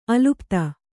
♪ alupta